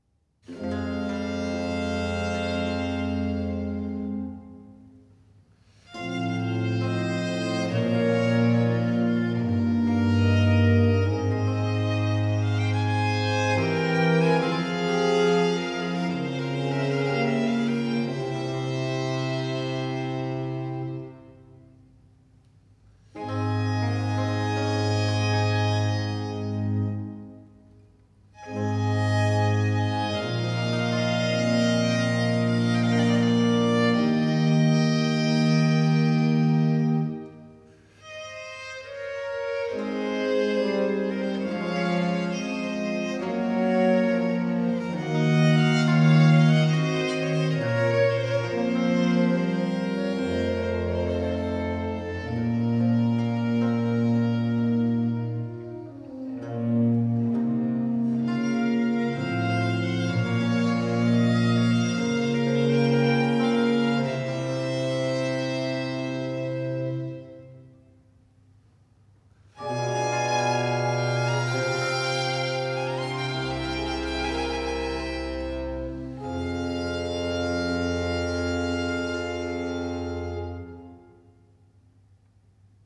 Adagio